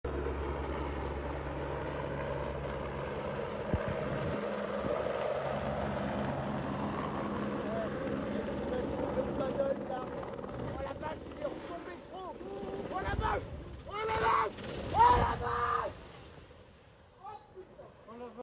A firefighting helicopter in Rosporden, sound effects free download